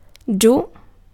Ääntäminen
US : IPA : /loʊ/ RP : IPA : /ləʊ/